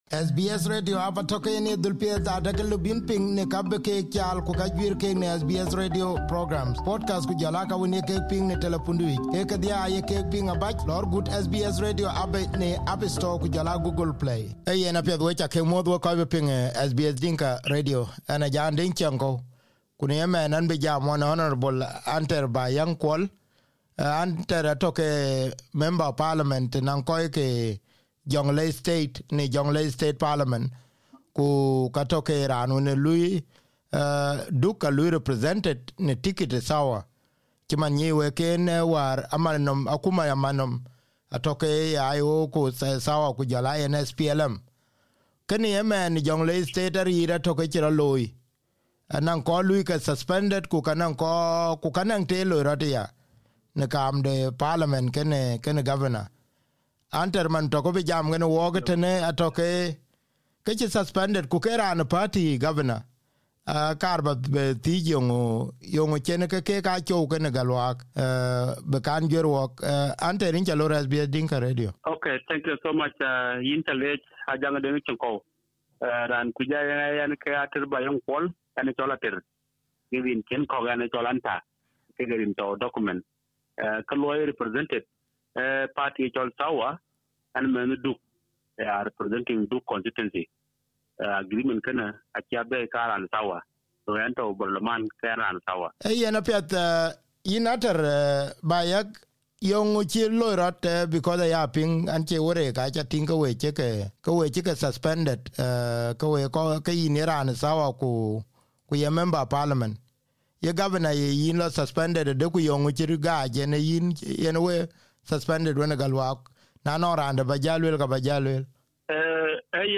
In an exclusive interview with Hon Ater Bayak, one of the MPs, on SBS Dinka Radio, Ater said the crisis between Governor and the two started after the parliament summoned the former Minister of Finance to answer questions about the delay of the state salaries.